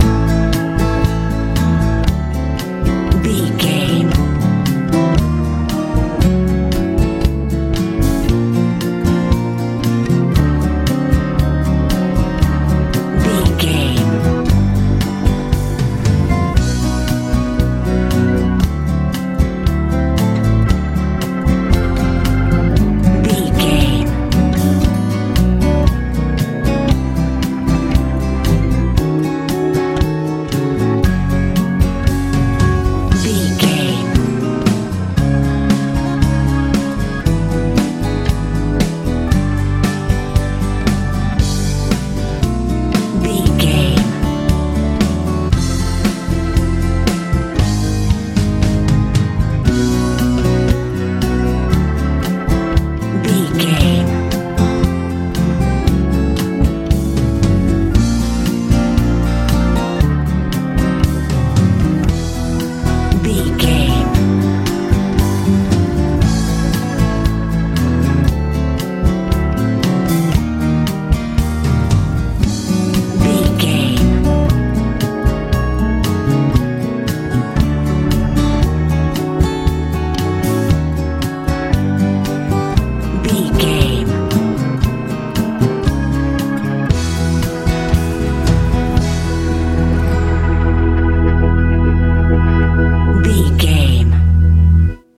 lite pop feel
Ionian/Major
soft
organ
acoustic guitar
bass guitar
drums
80s
90s